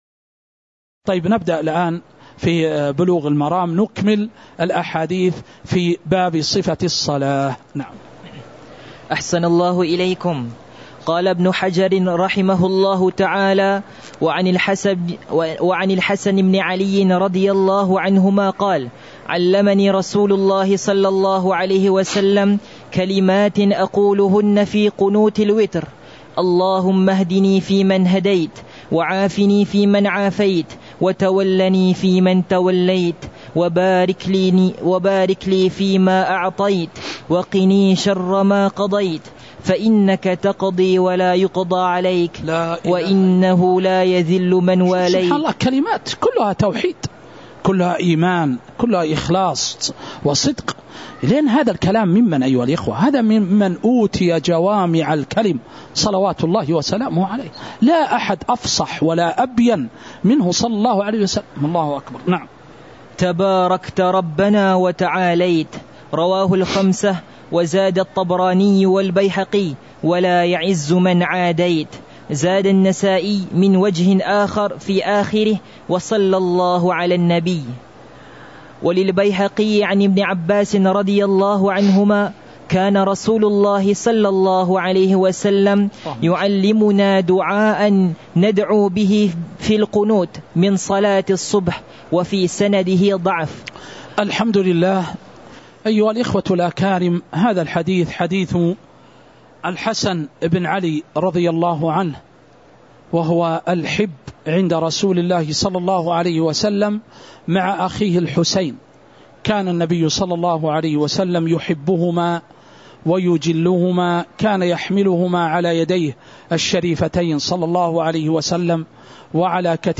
تاريخ النشر ٧ ربيع الثاني ١٤٤٥ هـ المكان: المسجد النبوي الشيخ